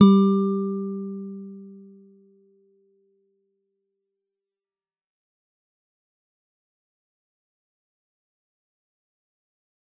G_Musicbox-G3-f.wav